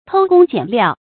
注音：ㄊㄡ ㄍㄨㄙ ㄐㄧㄢˇ ㄌㄧㄠˋ
偷工減料的讀法